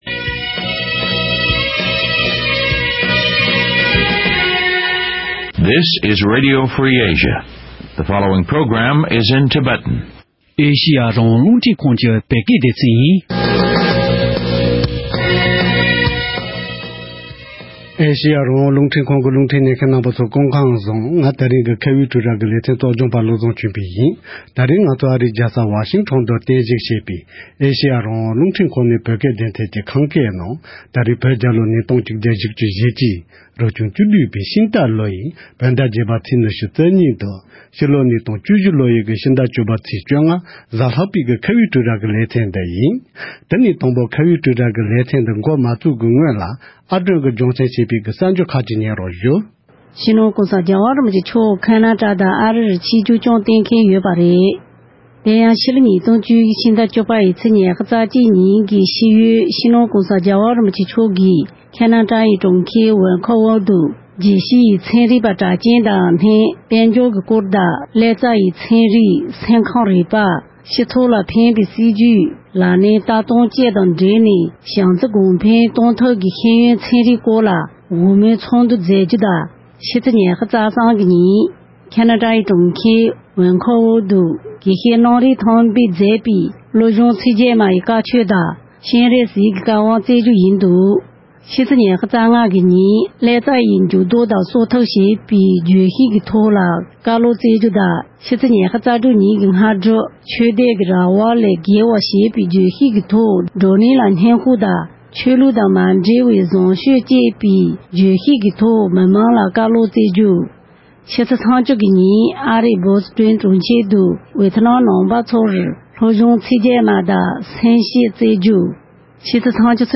བོད་དོན་ཐབས་རྩོད་ཆེད་བོད་པའི་སྤྱི་ཚོགས་ནང་ཆབ་སྲིད་ཀྱི་གོ་རྟོགས་དང་འབྲེལ་བའི་སྐོར་གལ་གནད་ཆེ་བའི་དྲི་བ་༡༢ཐོག་བཀའ་ཟུར་ཞིག་ནས་བཀའ་ལན་གནང་བ།